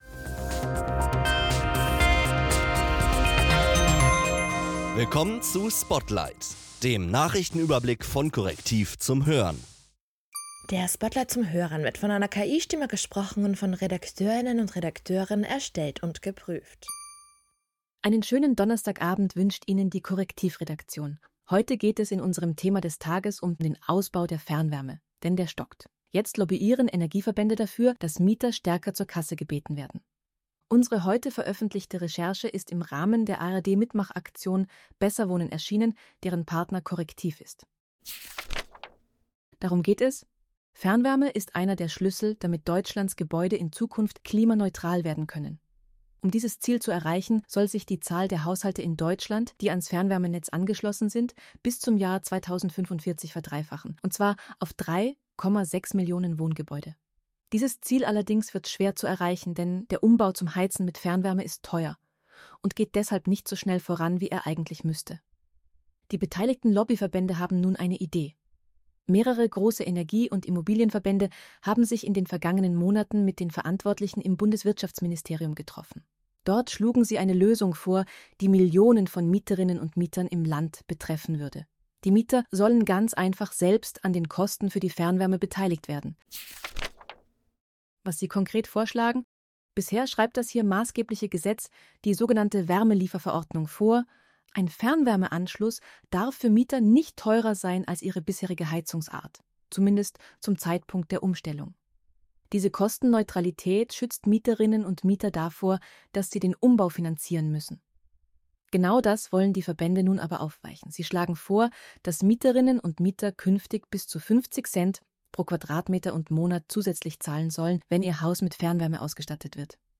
Diese für Audio optimierte Kompaktfassung des täglichen Spotlight-Newsletters ist von einer KI-Stimme eingelesen und von Redakteuren erstellt und geprüft.